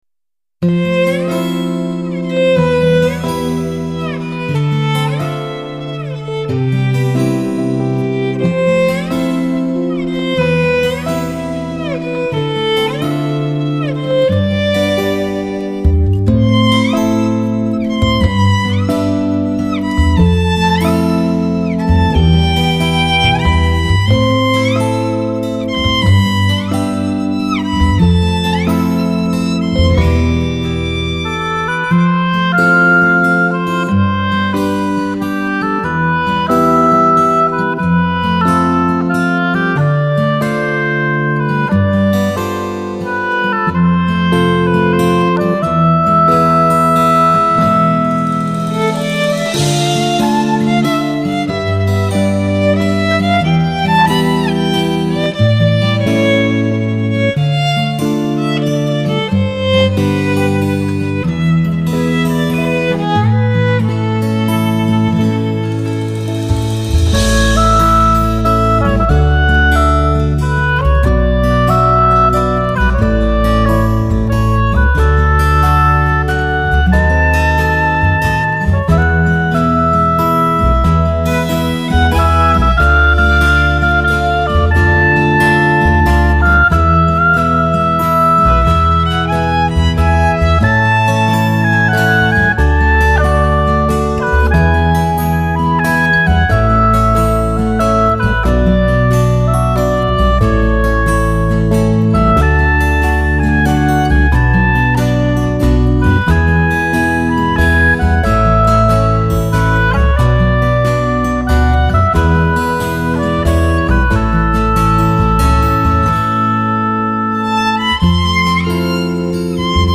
浪漫新世纪音乐